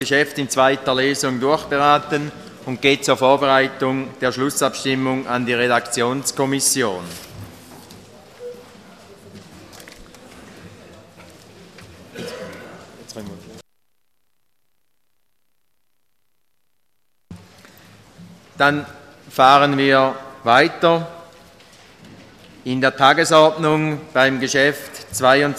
25.2.2013Wortmeldung
Session des Kantonsrates vom 25. bis 27. Februar 2013